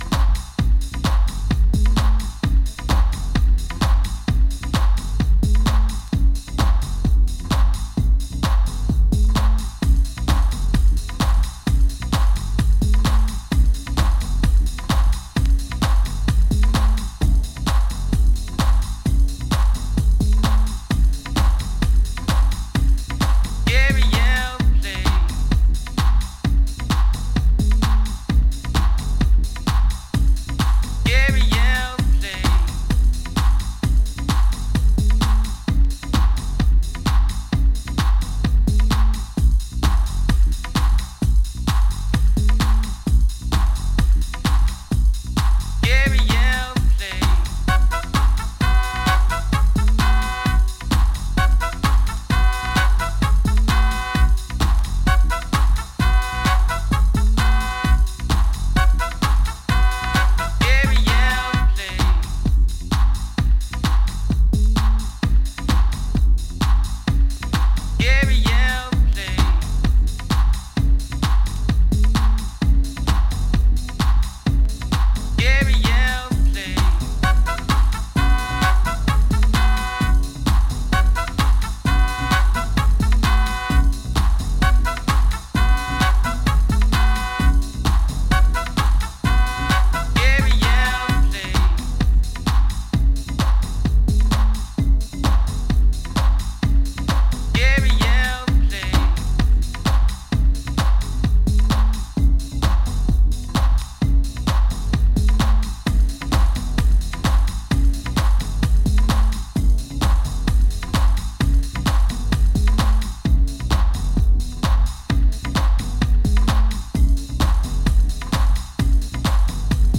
ディープで洗練されたソウル/ジャズ感を含んだ傑作ハウス作品です。